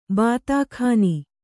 ♪ bātākhāni